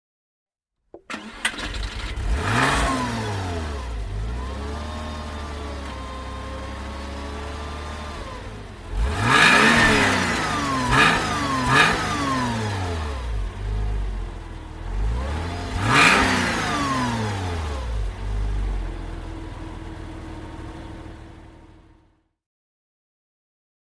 Porsche Boxster (1997) - Starten und Leerlauf
Porsche_Boxster_1997_v2.mp3